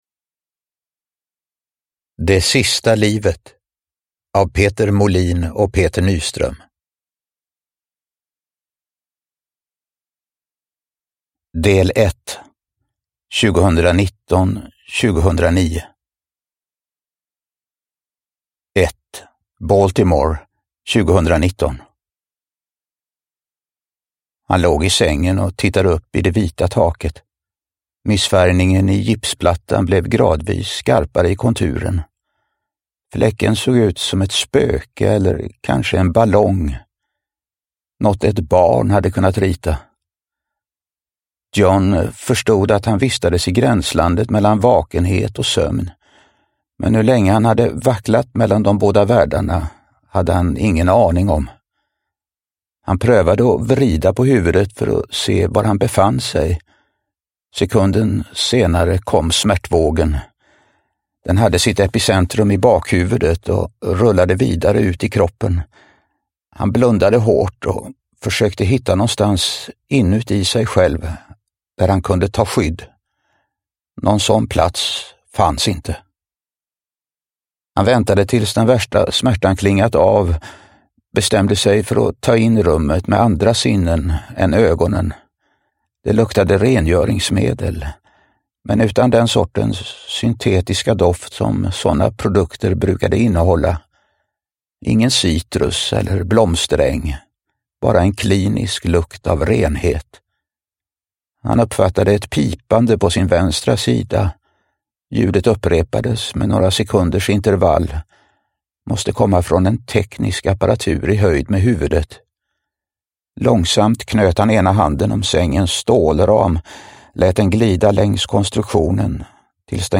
Det sista livet – Ljudbok – Laddas ner
Uppläsare: Reine Brynolfsson